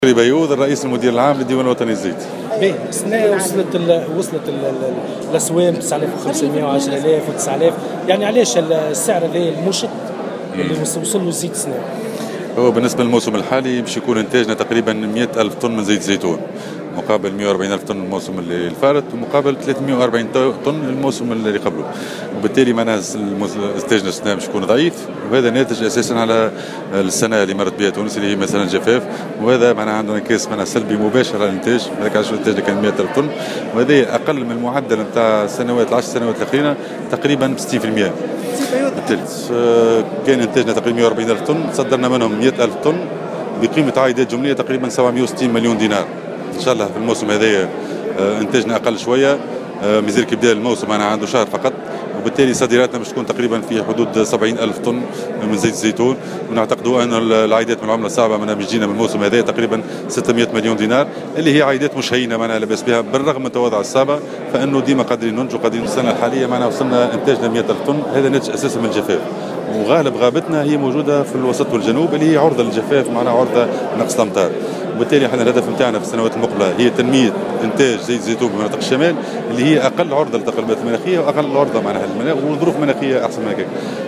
وأوضح في تصريحات صحفية بمناسبة الاحتفال باليوم العالمي للزيتون، أنه من المتوقع تراجع إنتاج تونس من زيت الزيتون خلال الموسم 2016/ 2017 الى 100 الف طن مقابل 140 ألف طن في الموسم 2015/ 2016 و340 ألف طن في 2014/ 2015.